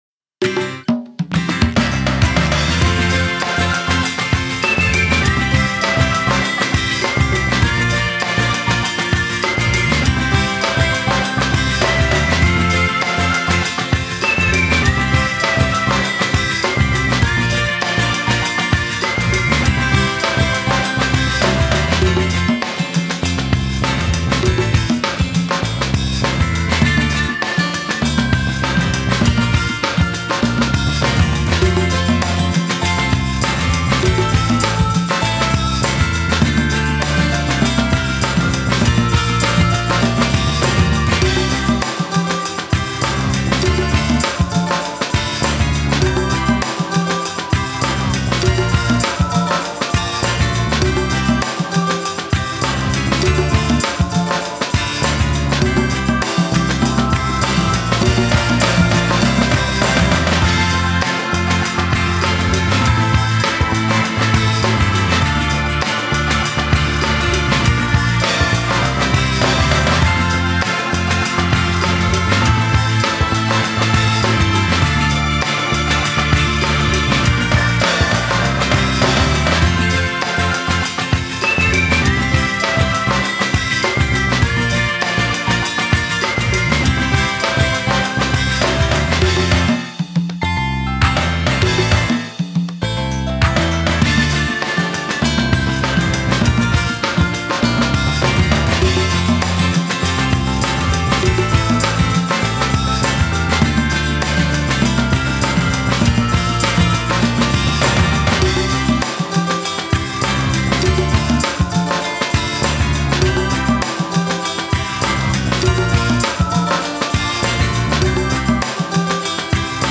■オケ
カラオケ